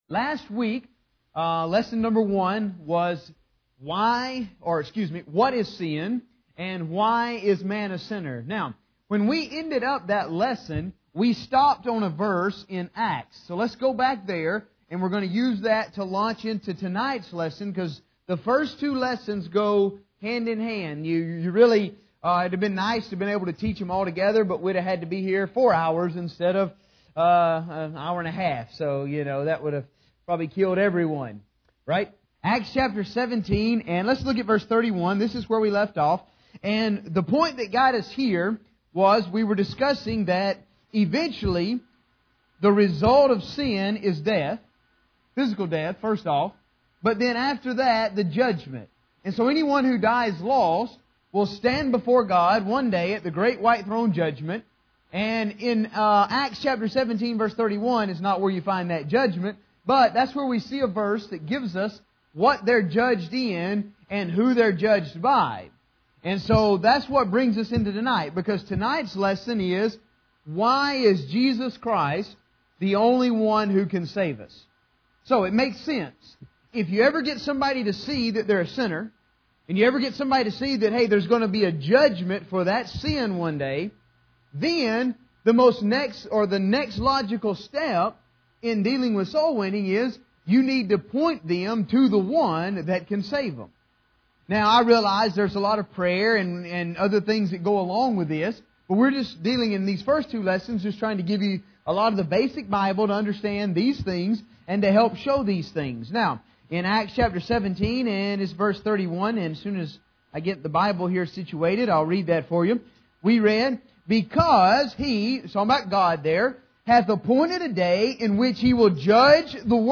Basic Bible for Soul Winning (Lesson #2)